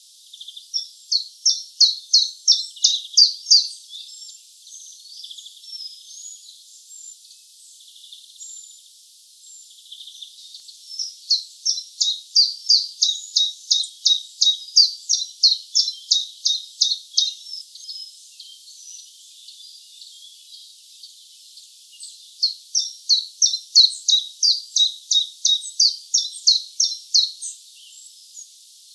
Phylloscopus collybita
E 29°34' - ALTITUDE: 0 m. - VOCALIZATION TYPE: full song.
Note the relatively variable shape of the song syllables. Background: Greenfinch, Silvidae.